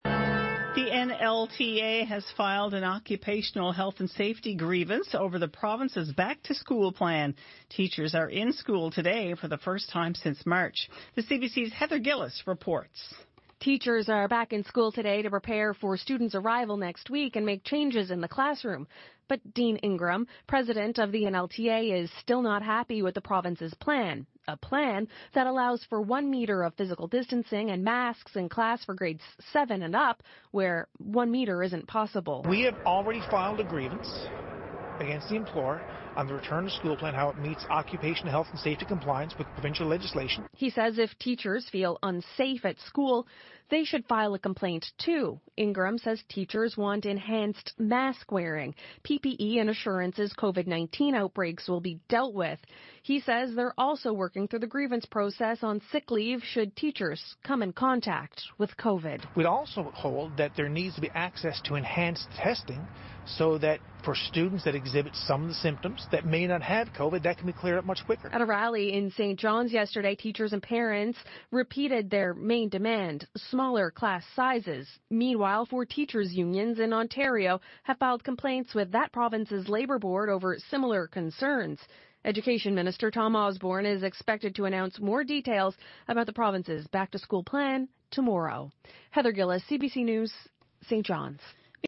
Media Interview - CBC 5pm News Sept 2, 2020